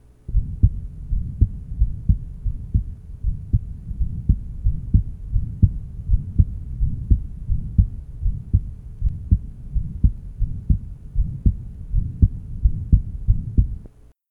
Date 1971 Type Systolic and Diastolic Abnormality Rheumatic Heart Disease 5 year old post rheumatic fever with aortic insufficiency and mitral insufficiency. Good slightly unusual aortic insufficiency murmur.